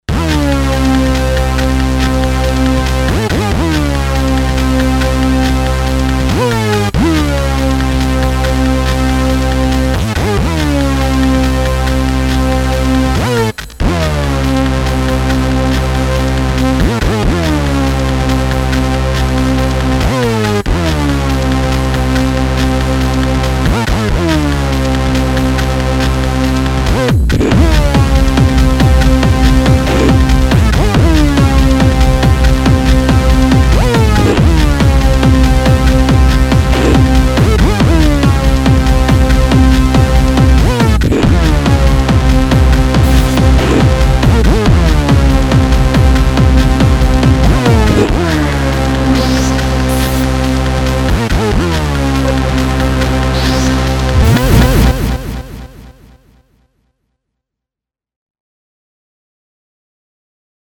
aaaand here is my edited version
i use saws and change pwm speed, i add noise and remove osc drift ala juno and rez peak, i also adjusted a tiny bit the chorus and pitch env, small changes but preferable to me and a tad smoother - let me know